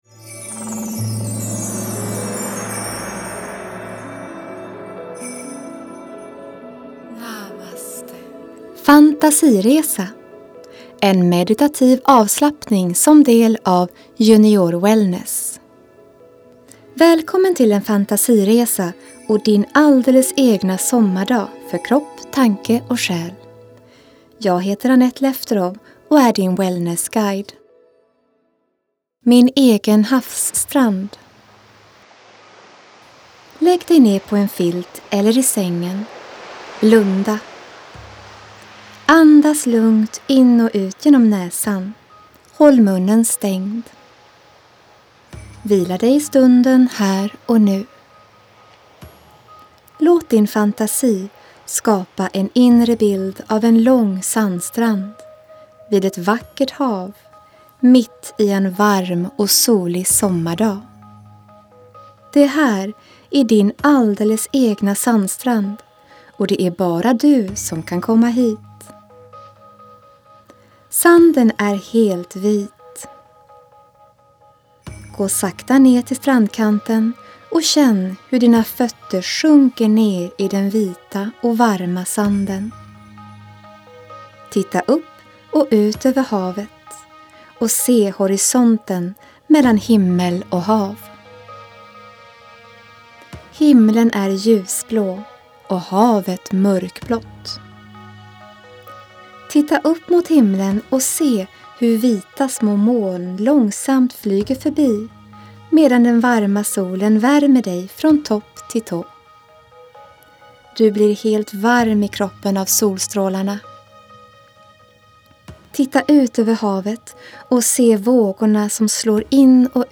IMG_2815I junioryoga använder jag fantasiresor som en del av upplevelsen, en guidad meditation. Här får du en fantasiresa som ni kan lyssna på tillsammans.